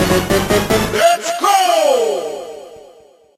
8bit_start_vo_03.ogg